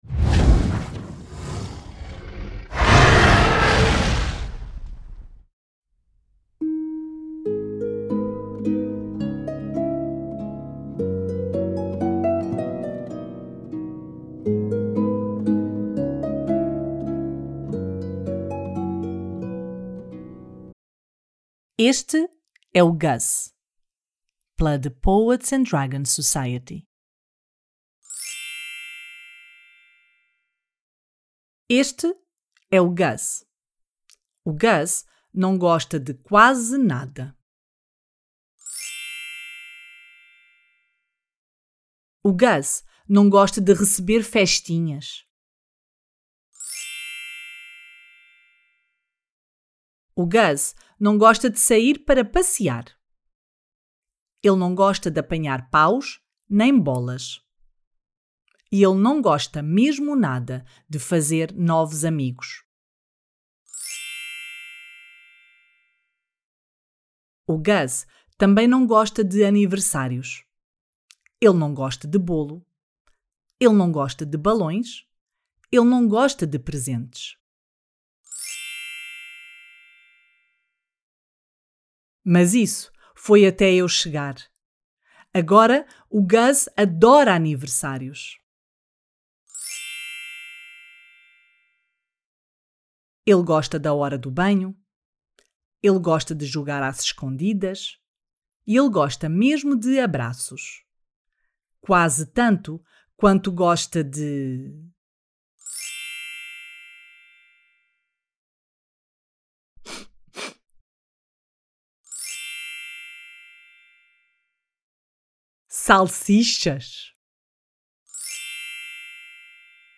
Gus-Audiobook.mp3